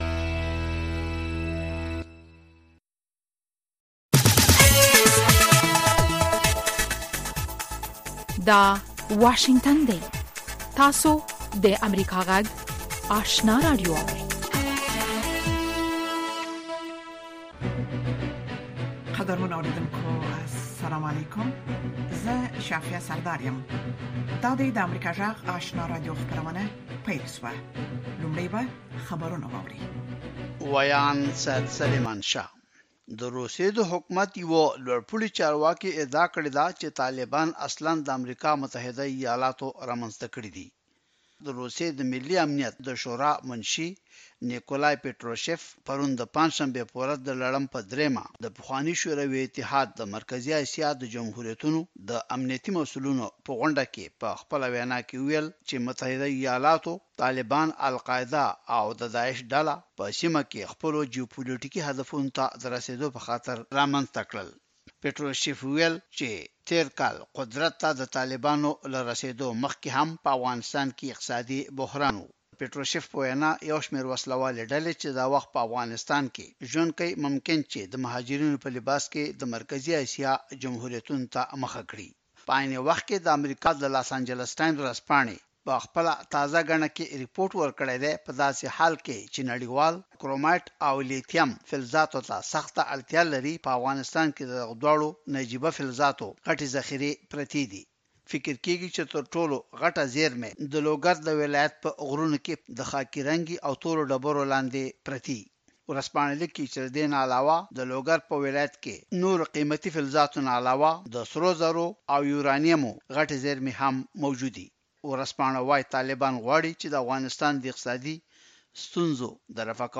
سهارنۍ خبري خپرونه